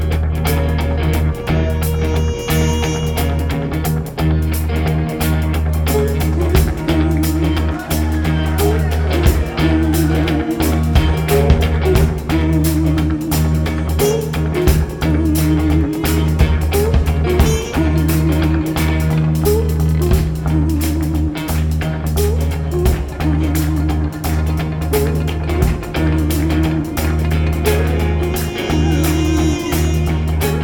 "enPreferredTerm" => "Chanson francophone"